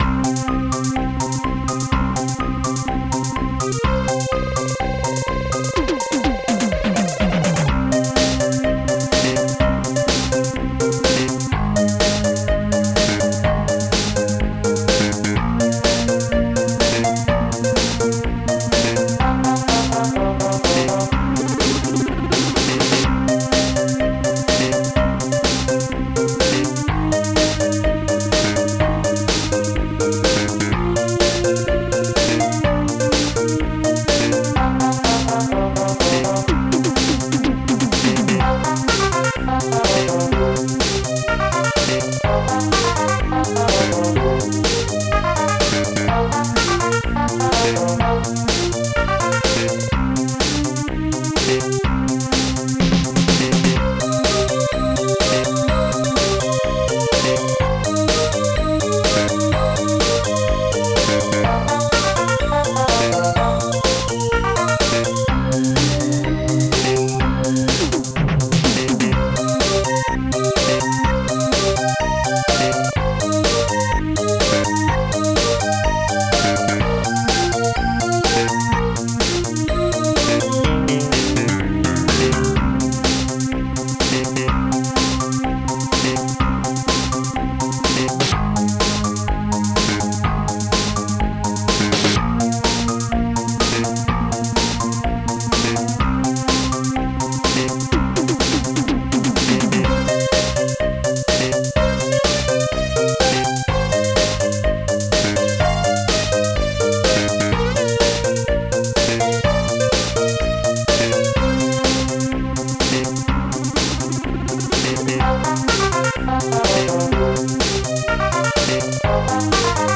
Protracker Module  |  1991-06-17  |  139KB  |  2 channels  |  44,100 sample rate  |  2 minutes, 49 seconds
st-01:bassdrum2
st-02:snare6
st-01:strings2
st-03:flickbass
st-01:hihat2
st-03:harphigh